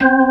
FST HMND C4.wav